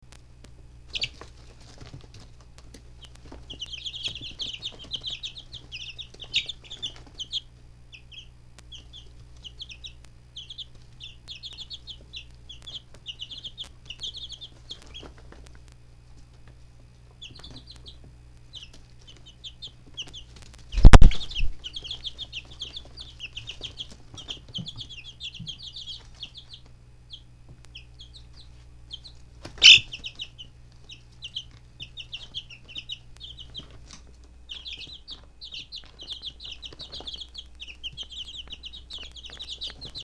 The lightest brown one has a habit of cheeping loudly and steadily when it is upset.
Click here to download a wav file of their chirping.